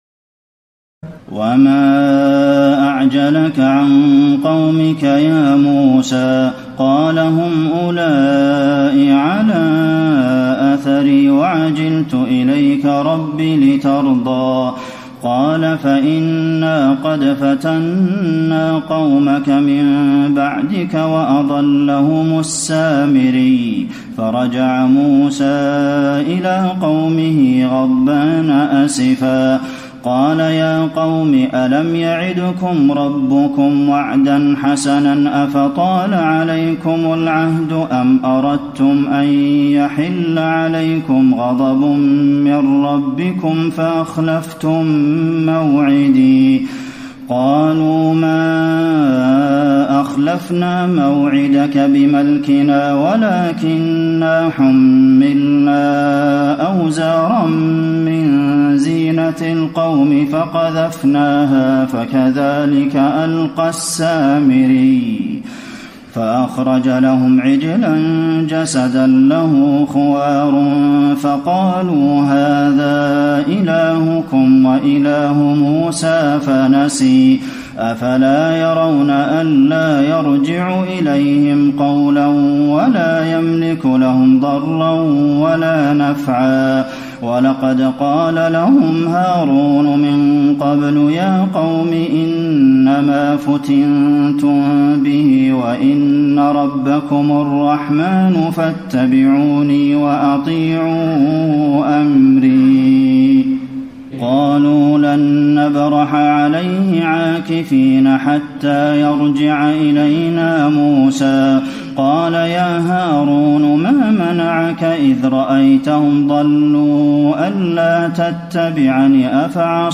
تراويح الليلة السادسة عشر رمضان 1434هـ من سورتي طه (83-135) و الأنبياء (1-50) Taraweeh 16 st night Ramadan 1434H from Surah Taa-Haa and Al-Anbiyaa > تراويح الحرم النبوي عام 1434 🕌 > التراويح - تلاوات الحرمين